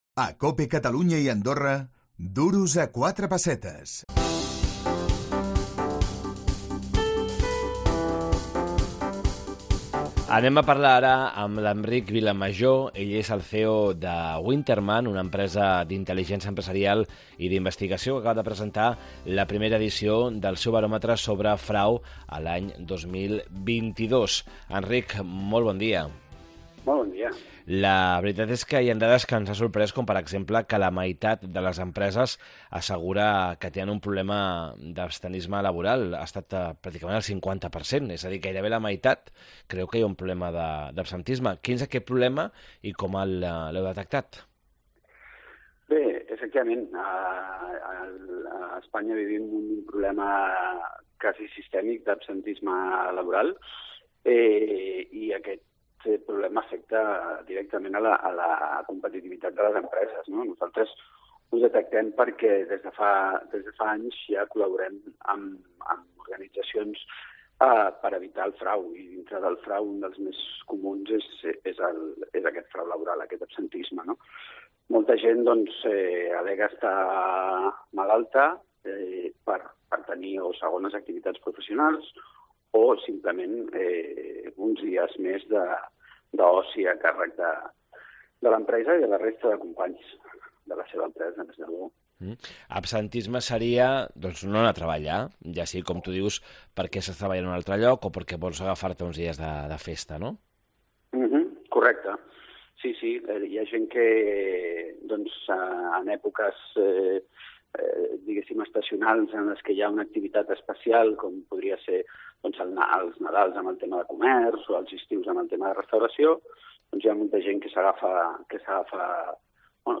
Duros a quatre pessetes, el programa d’economia de COPE Catalunya i Andorra.